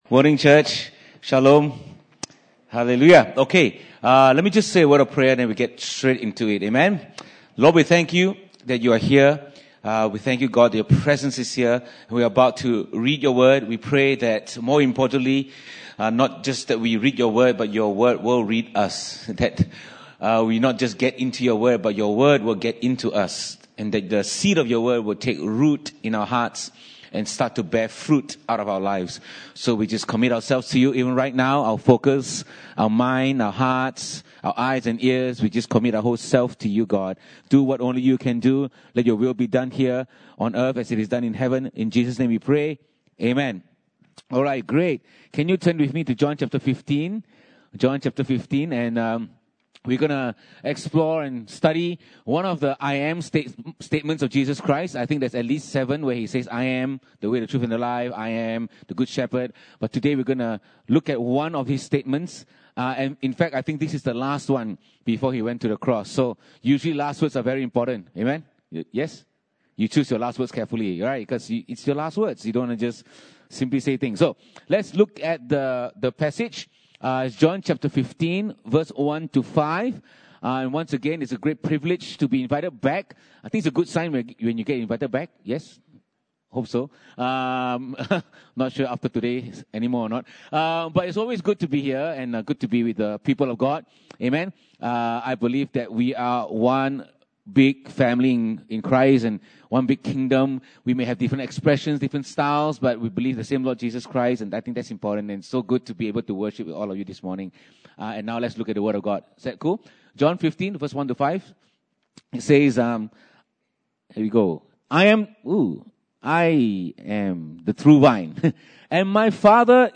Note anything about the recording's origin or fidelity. Passage: John 15:1-5 Service Type: Sunday Service (Desa ParkCity) « Boot Camp For Christians What to do with this man